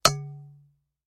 Звук удара деревянной ложки о край кастрюли